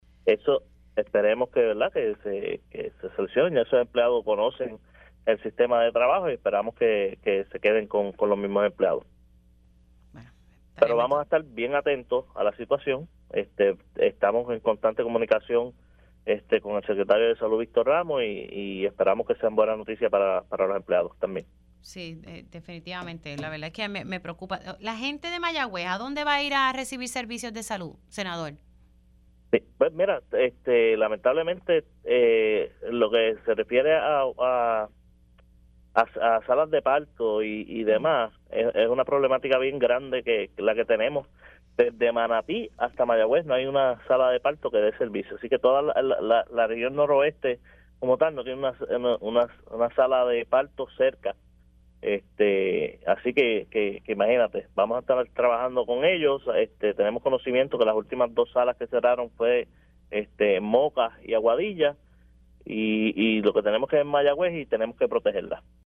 El senador por el Distrito de Mayagüez-Aguadilla, Jeison Rosa, confirmó en Pega’os en la Mañana que en la tarde de hoy, jueves, el municipio de Mayagüez escogerá a un nuevo administrador para el hospital San Antonio, cuyas facilidades y administración fueron transferidas el pasado viernes para evitar su cierre.
410-JAISONROSA-SENADOR-MAYAGUEZ-DESDE-MANATI-A-MAYAGUEZ-NO-HAY-UNA-SALA-DE-PARTO.mp3